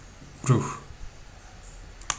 u
ruh cool
Tr_tr_ruh.ogg.mp3